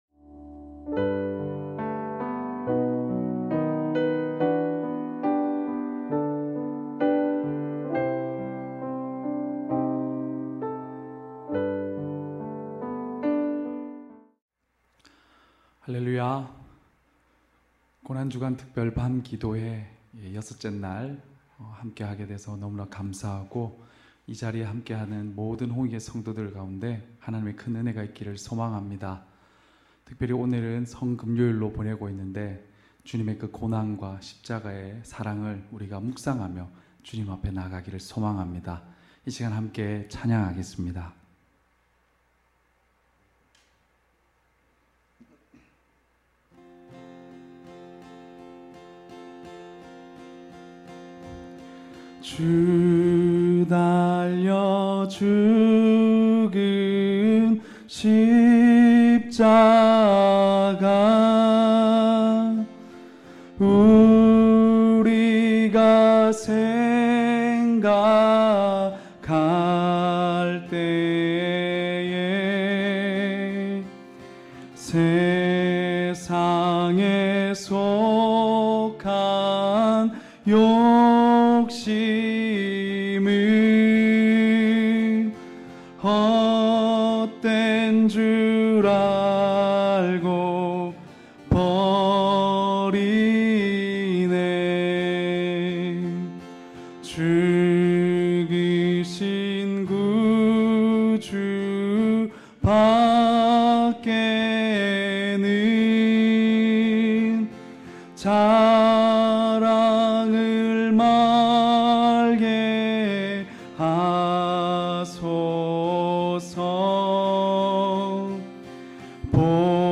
고난주간 특별 밤기도회 여섯째날.mp3